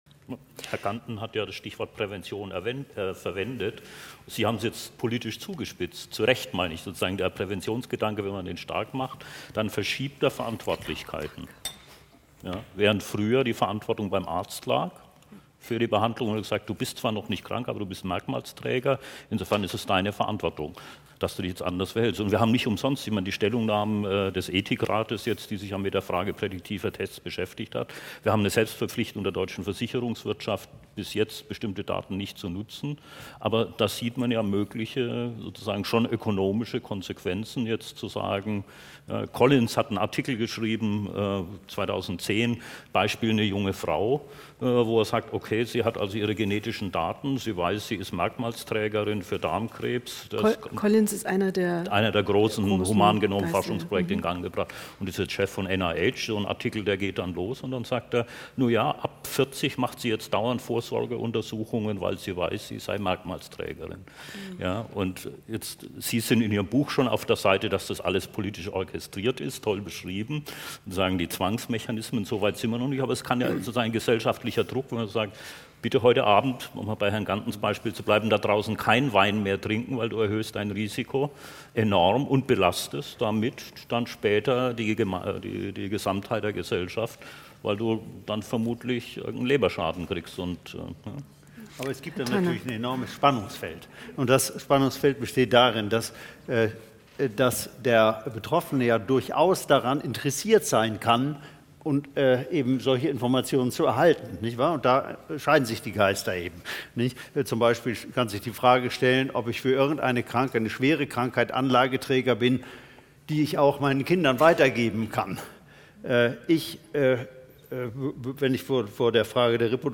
Das Wissenschaftsjahr Gesundheitsforschung bietet nun komplette Audiomitschnitte der Podiumsdiskussion an.